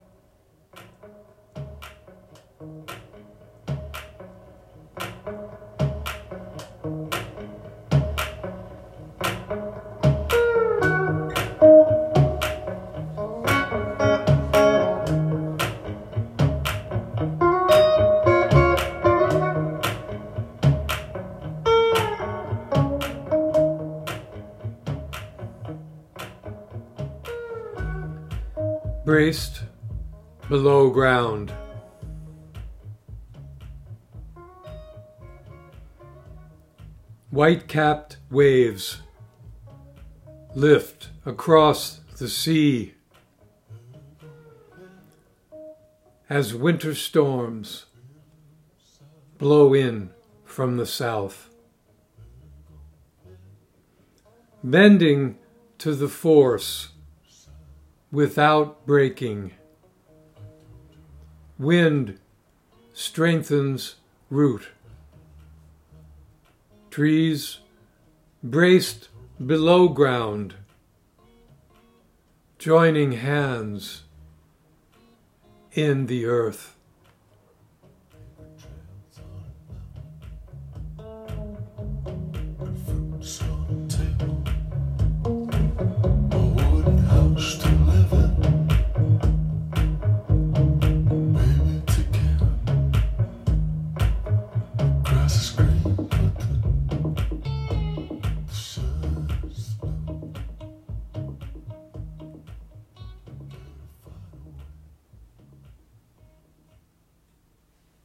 Reading of “Braced Below Ground” with music by Darkside